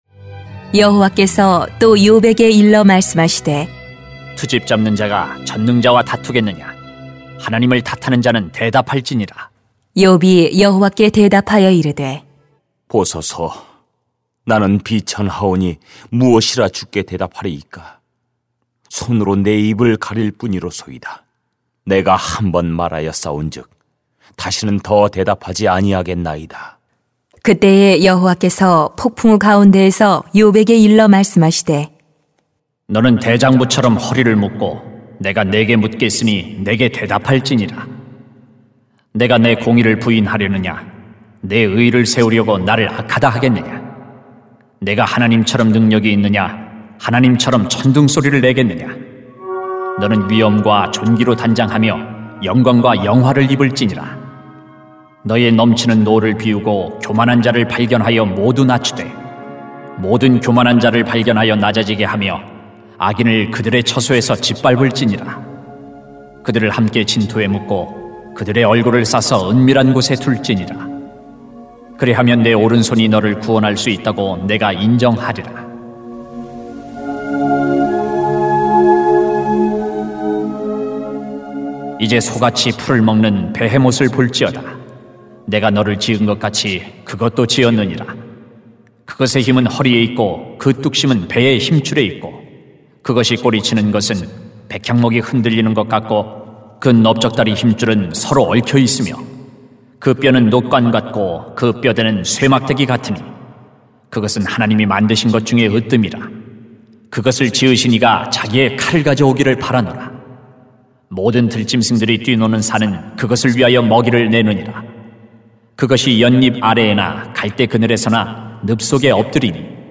[욥 40:1-24] 크신 하나님을 비난할 수 없습니다 > 새벽기도회 | 전주제자교회